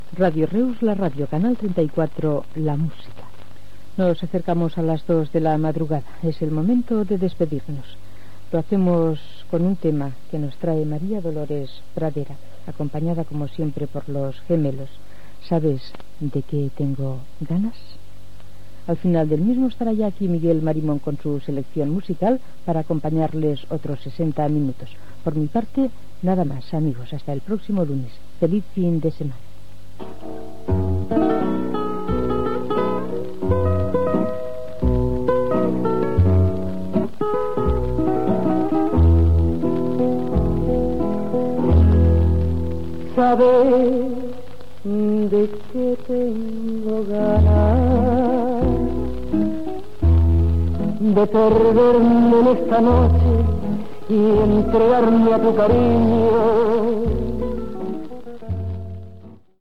Identificació i tema musical